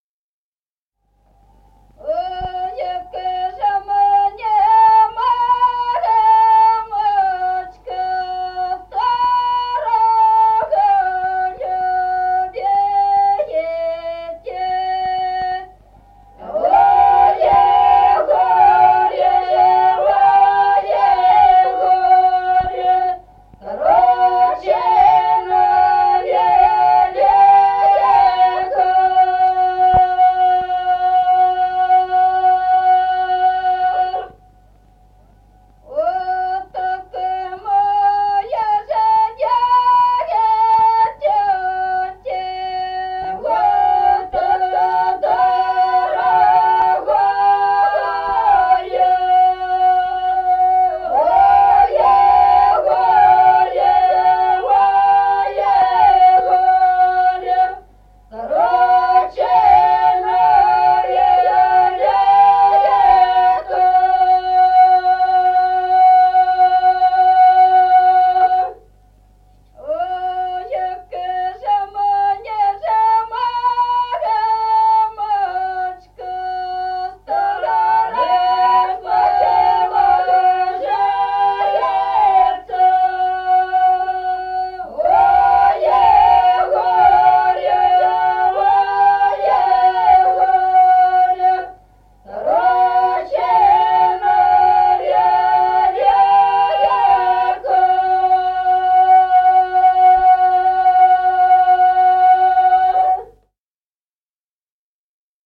Народные песни Стародубского района «Ой, як же мне, мамочка», карагодная, игровая.
1953 г., с. Остроглядово.